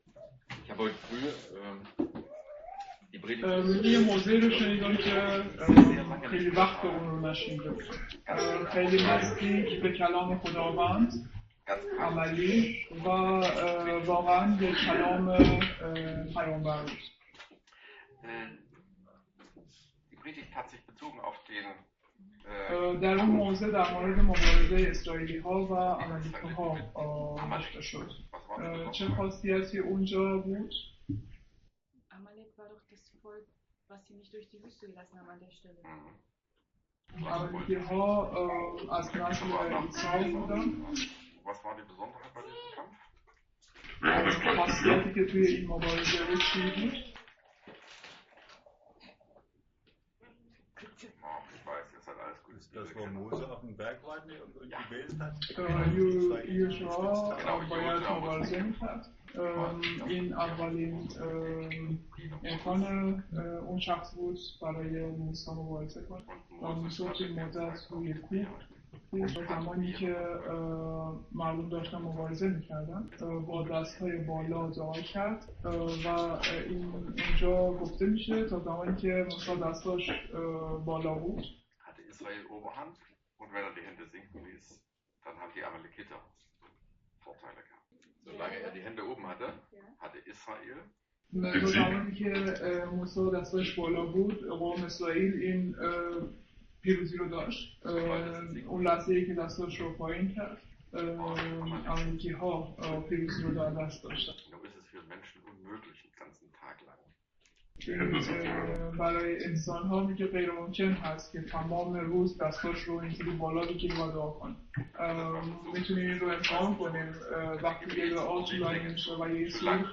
Korinther 10, 3 – 6 | Übersetzung in Farsi